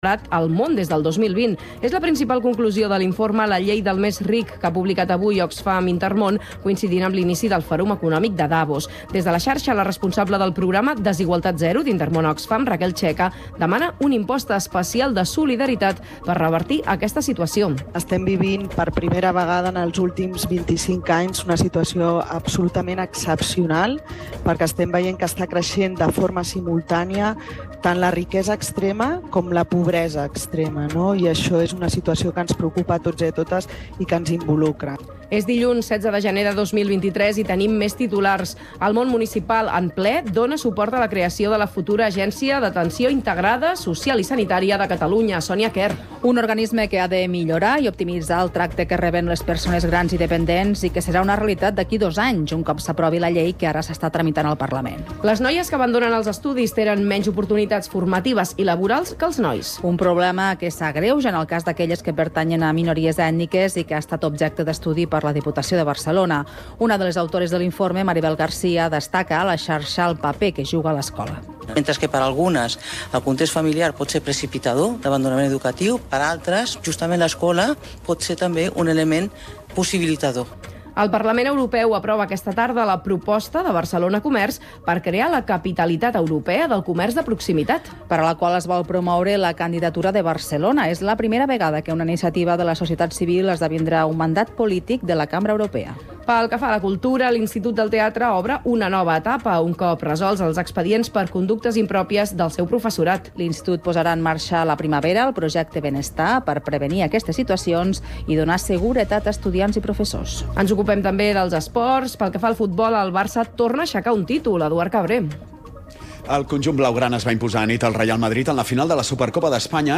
Informatiu radiofònic que dóna prioritat a l’actualitat local.